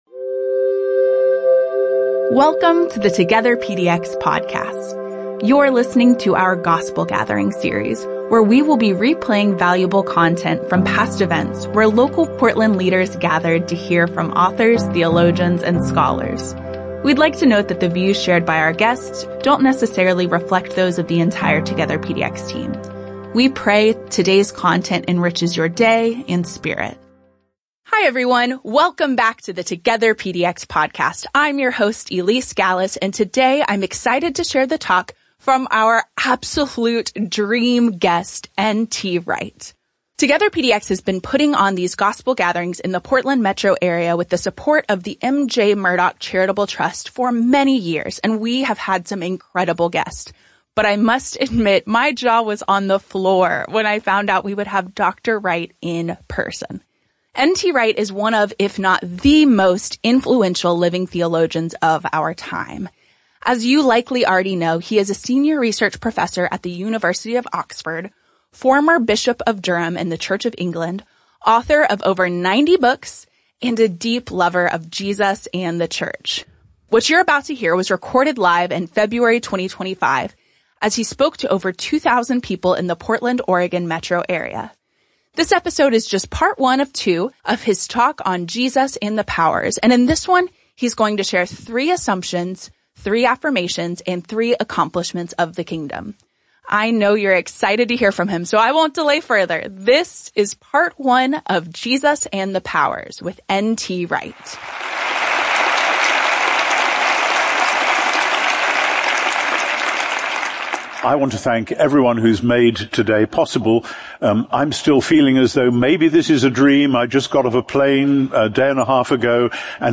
N.T. Wright joined more than 2,000 church leaders from the Portland, OR metro area in February 2025. This is part 1 of his talk, "Jesus and the Powers."